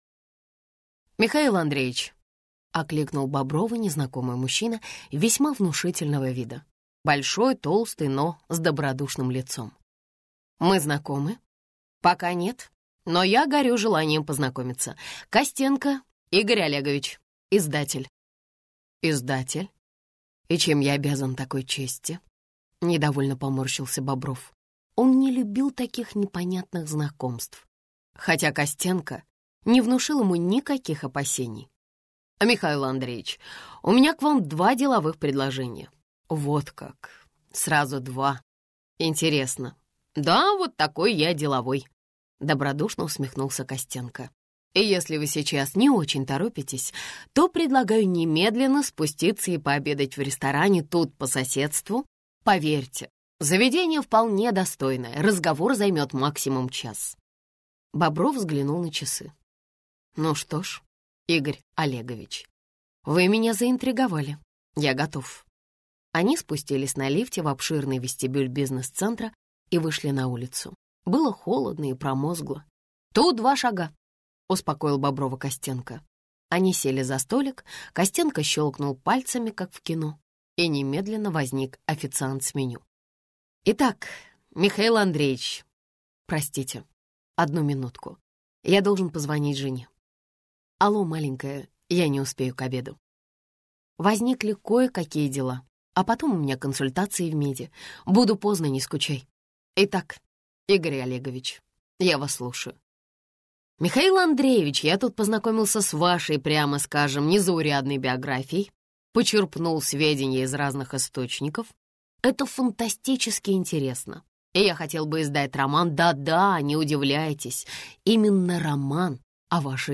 Аудиокнига «Шпионы тоже лохи» в интернет-магазине КнигоПоиск ✅ в аудиоформате ✅ Скачать Шпионы тоже лохи в mp3 или слушать онлайн